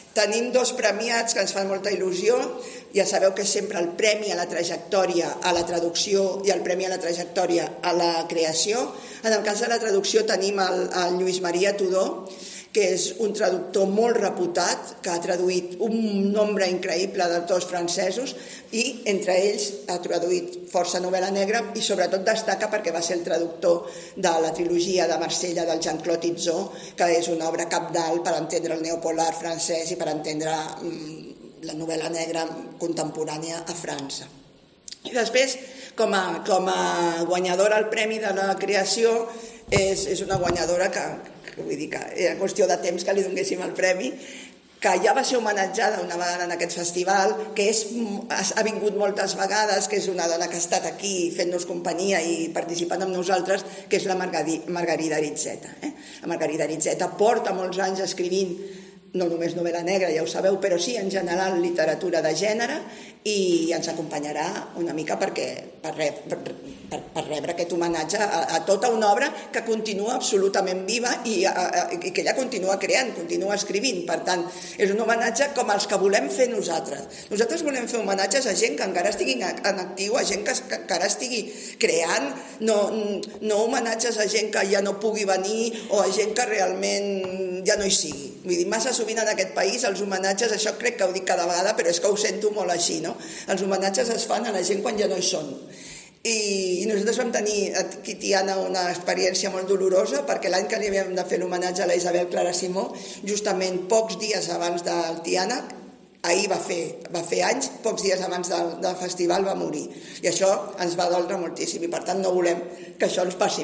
Com ja és habitual es lliuraran els premis del Tiana Negra, un clàssic del festival: el XIII Premi Agustí Vehí-Vila de Tiana i els premis a la Trajectòria a la traducció i la creació. Enguany, durant la roda de premsa ja han avançat els noms d’aquests dos últims premiats: